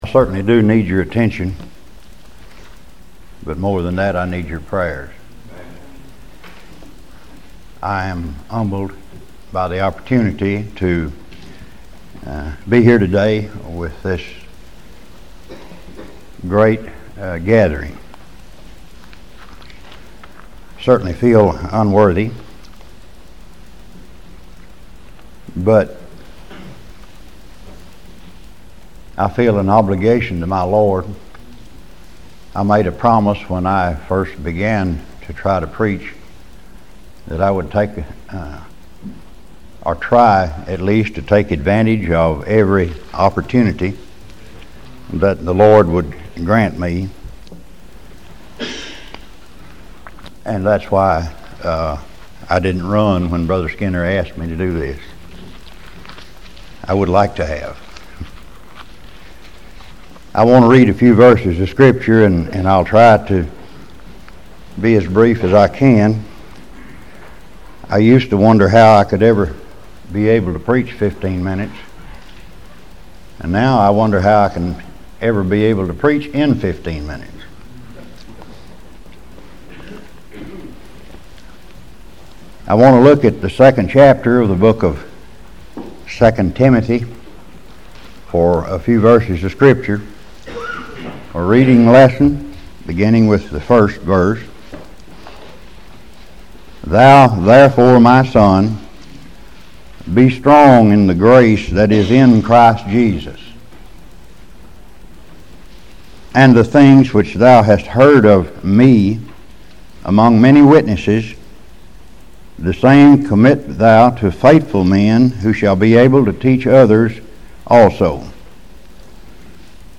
Friday Morning devotional from 2001 session of the Old Union Ministers School.